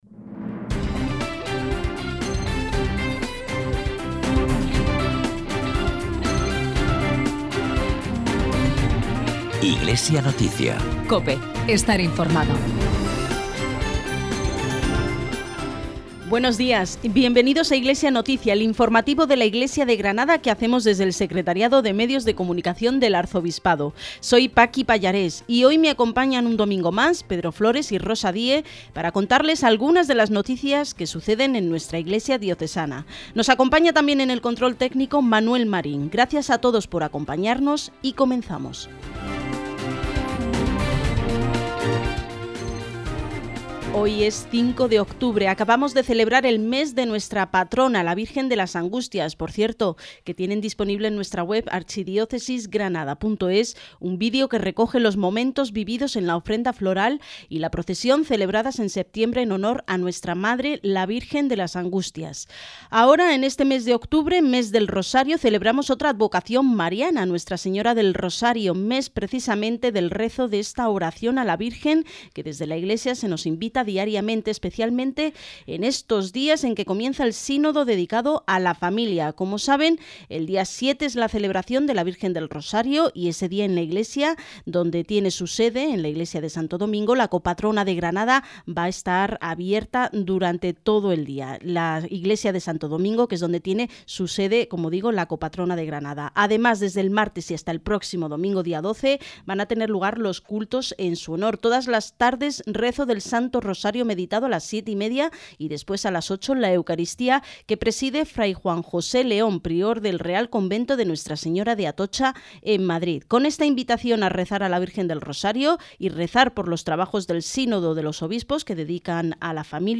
Programa informativo que realiza el Secretariado de Medios de Comunicación Social del Arzobispado de Granada, emitido en COPE Granada el domingo 5 de octubre de 2014.